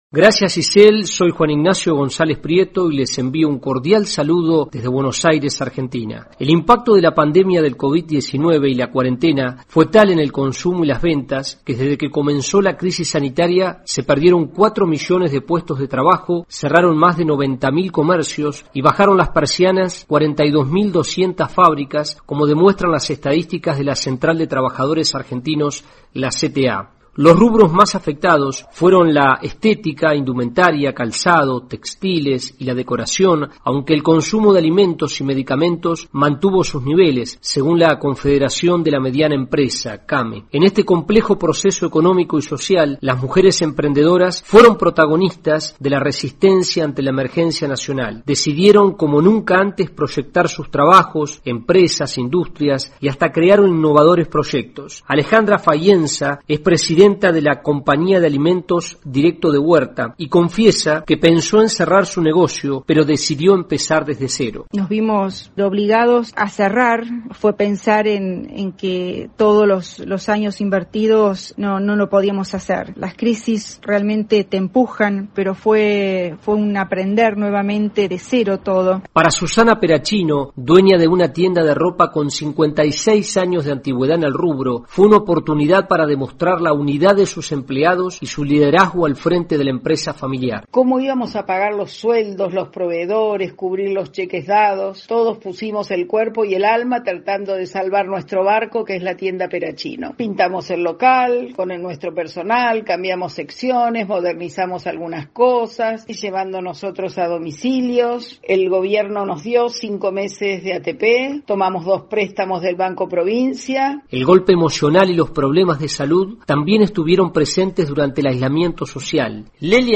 AudioNoticias
El COVID-19 provocó una mayoría de efectos negativos pero al mismo tiempo fue tan grande el desafío que despertó el espíritu emprendedor de muchas mujeres. Reportaje especial